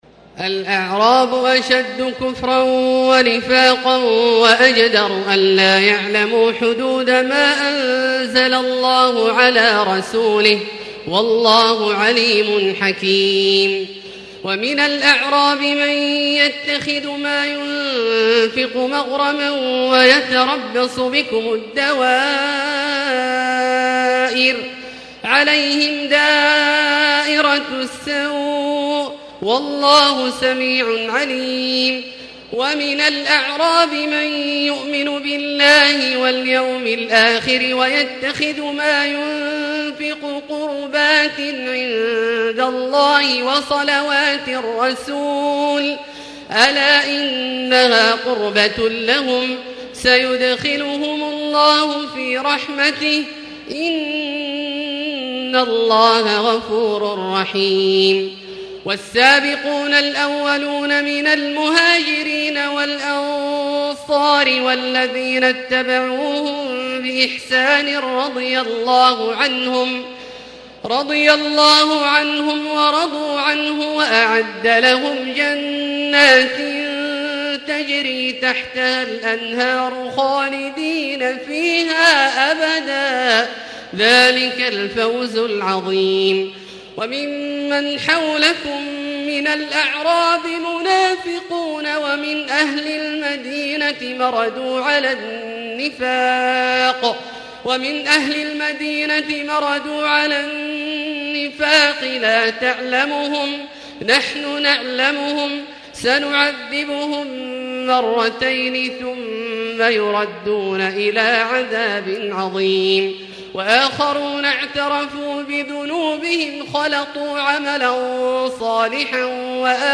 تراويح الليلة العاشرة رمضان 1440هـ من سورتي التوبة (97-129) و يونس (1-25) Taraweeh 10 st night Ramadan 1440H from Surah At-Tawba and Yunus > تراويح الحرم المكي عام 1440 🕋 > التراويح - تلاوات الحرمين